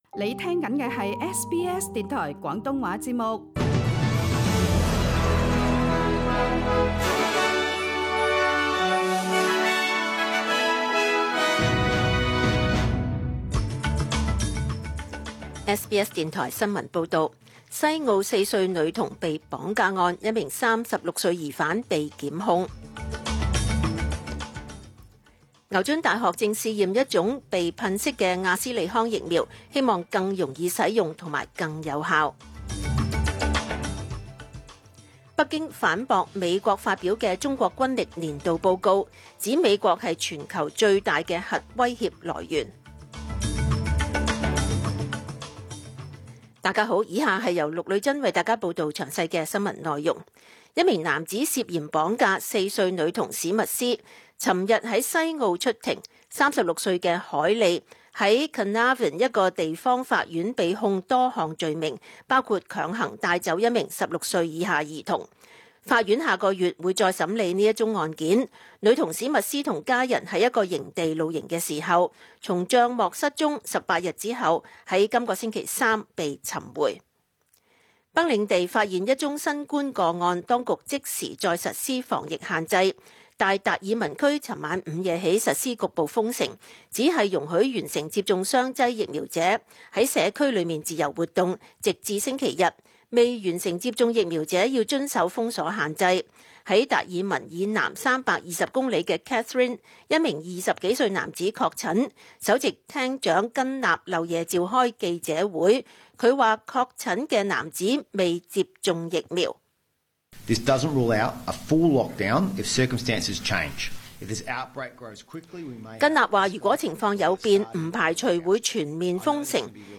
SBS 廣東話節目中文新聞 Source: SBS Cantonese